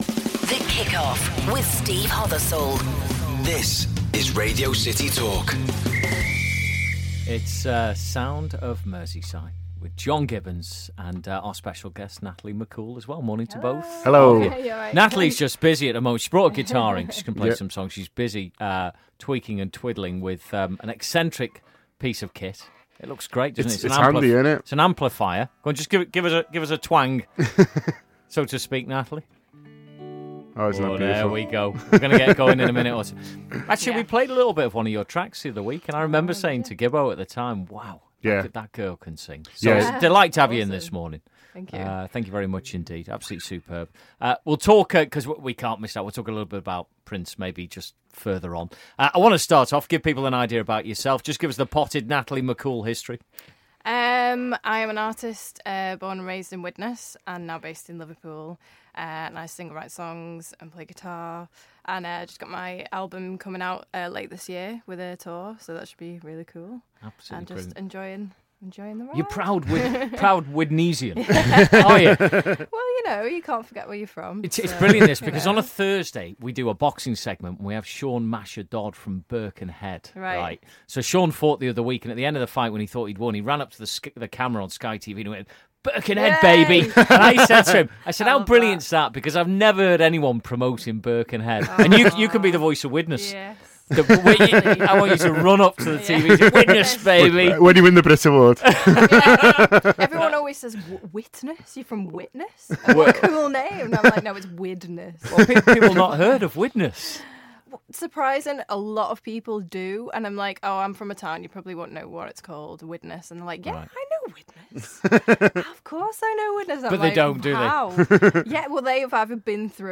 and brought her guitar with her